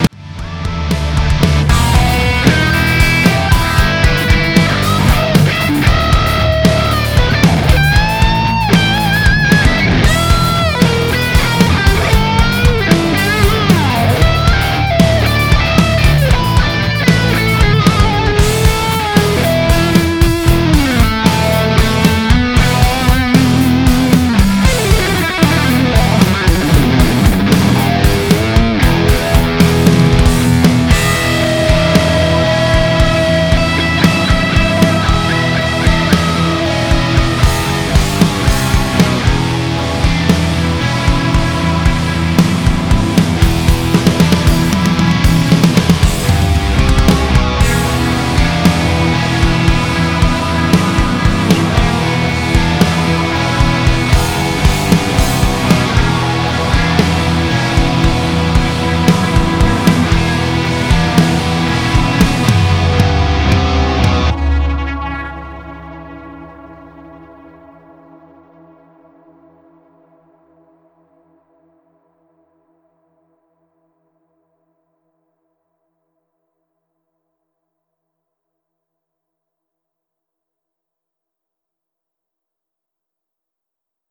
недалече писал соло на аранж свой же ... с первого дубля записал , чистейший импровиз , 4 дольная сетка , медленный ритм... проанализируй нотки ....
solo.mp3